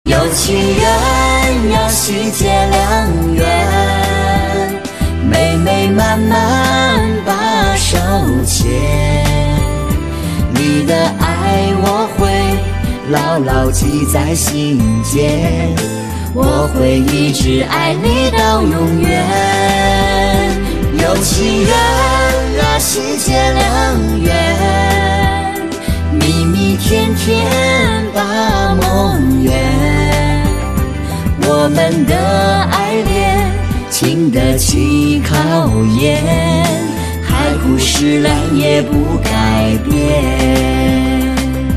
M4R铃声, MP3铃声, 华语歌曲 48 首发日期：2018-05-15 05:32 星期二